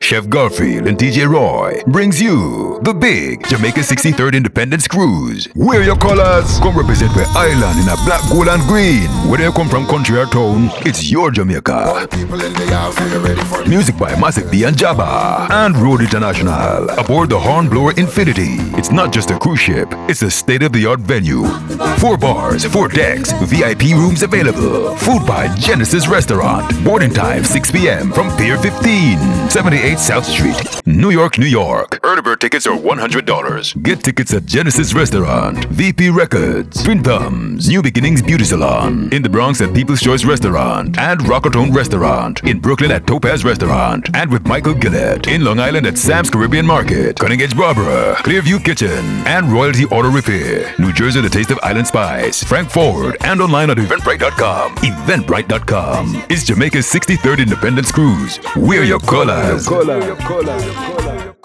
Radio & TV Commercial Voice Overs Talent, Artists & Actors
English (Caribbean)
Adult (30-50) | Older Sound (50+)